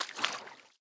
swim1.ogg